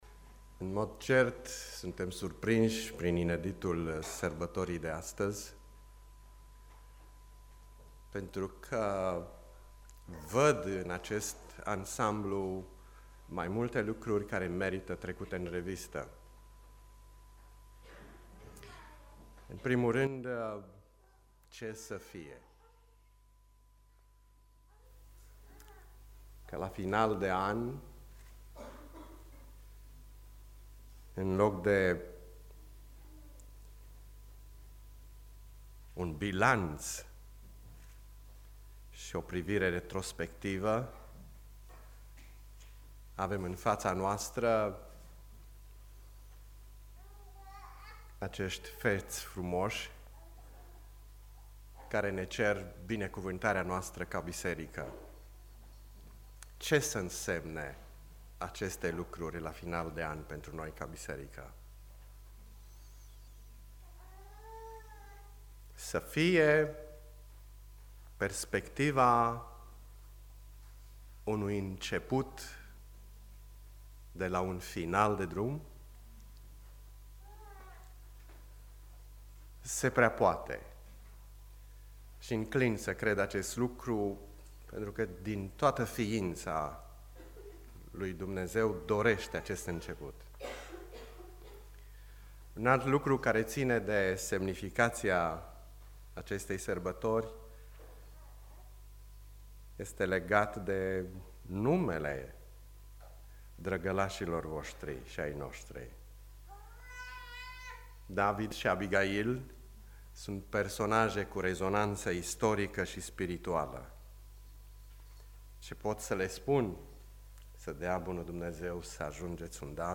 Predica Pilda comorii ascunse